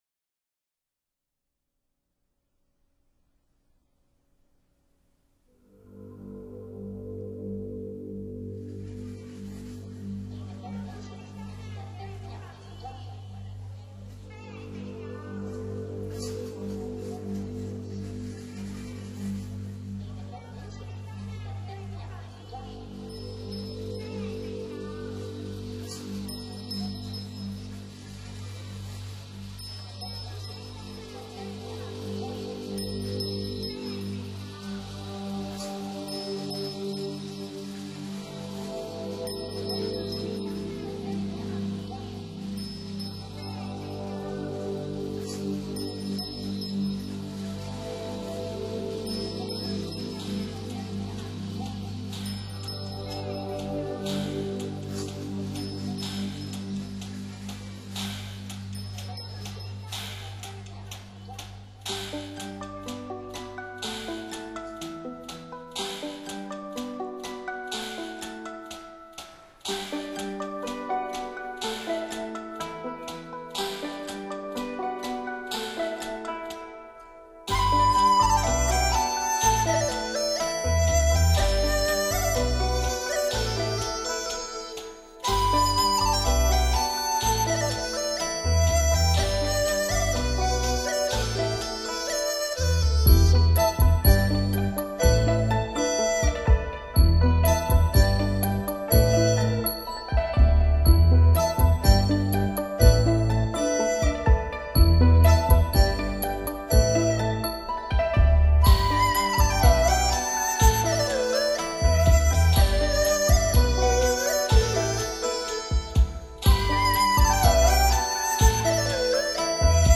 中国民乐和合成器音乐的演绎
中国传统乐器与现代乐器的完美组合，曲目都是我们熟悉不能再熟悉
中国扬琴
竹笛
二胡
合成器